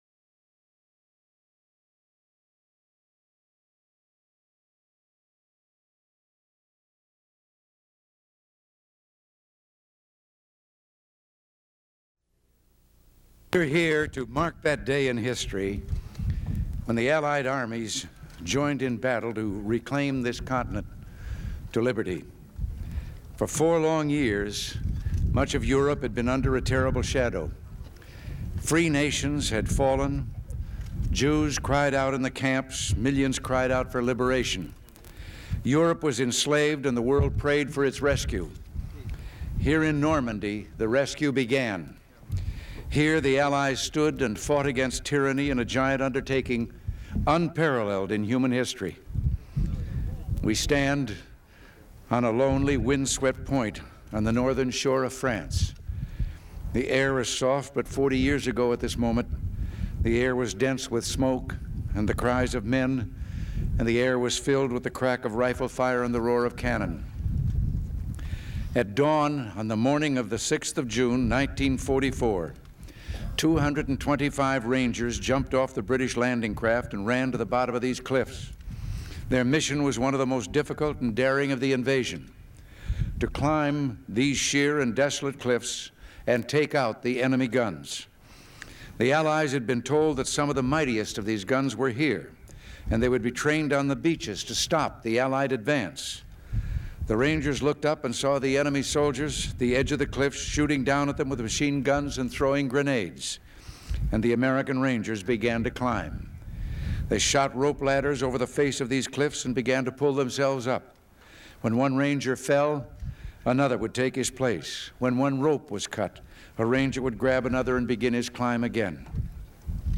Presidential Speeches | Ronald Reagan Presidency